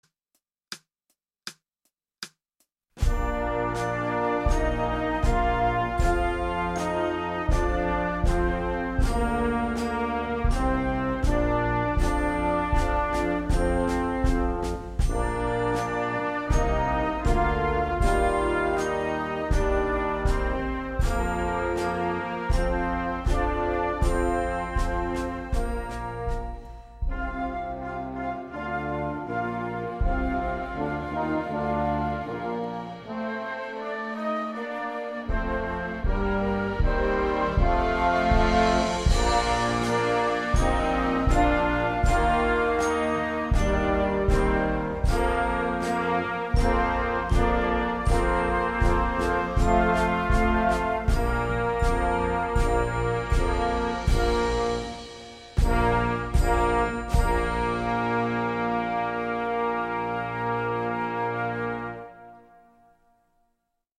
Trombone Base